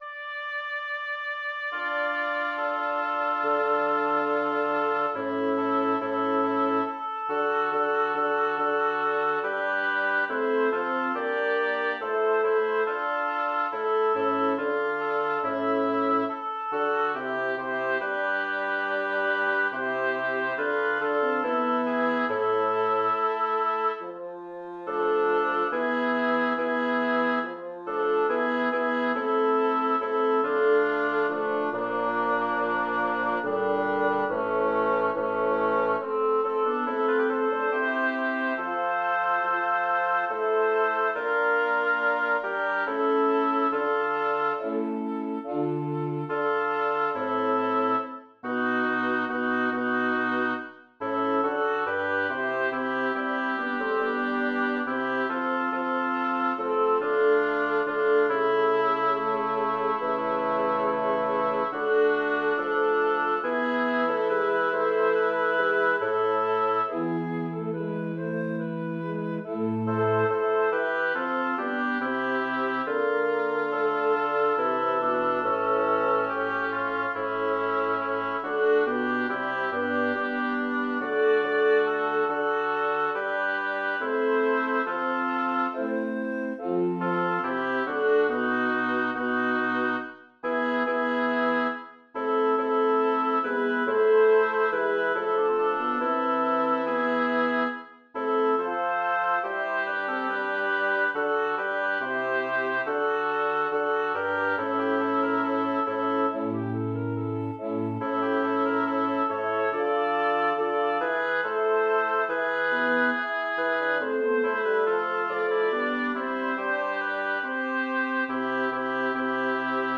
Number of voices: 8vv Voicing: SATB.SATB Genre: Secular, Madrigal
Language: Italian Instruments: A cappella